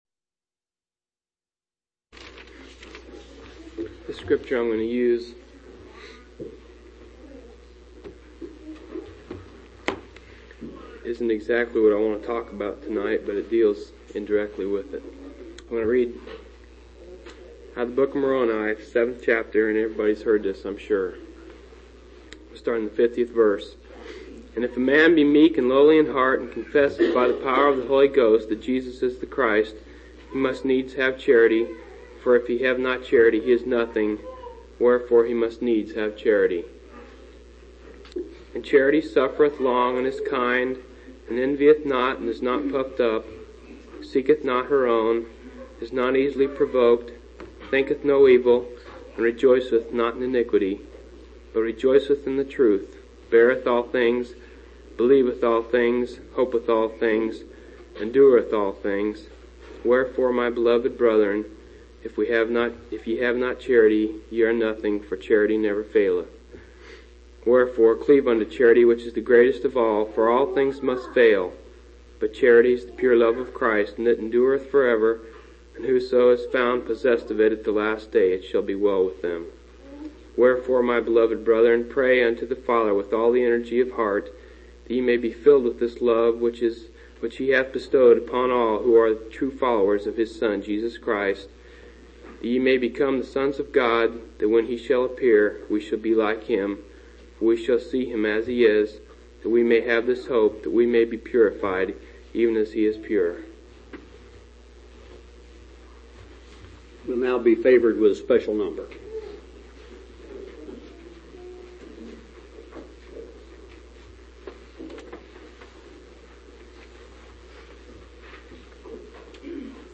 11/20/1983 Location: Phoenix Local Event